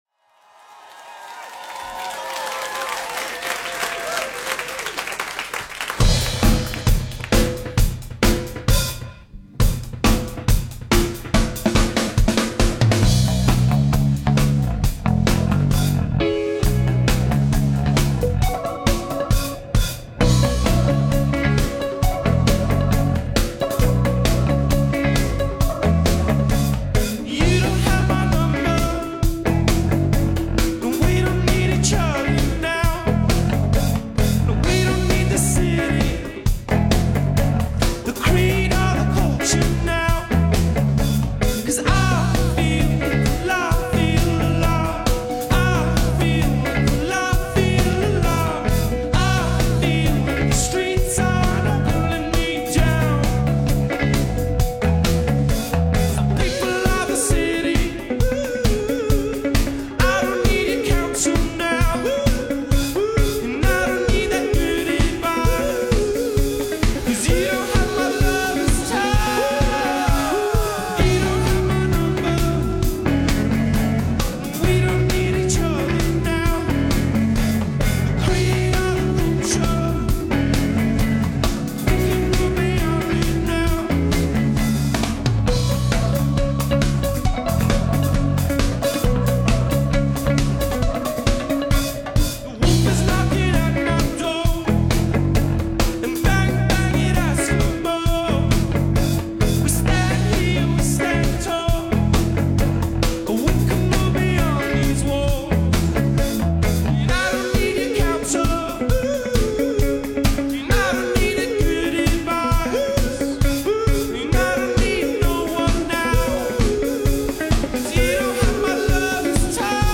recorded and streamed live
Dance-Punk on a futuristic scale.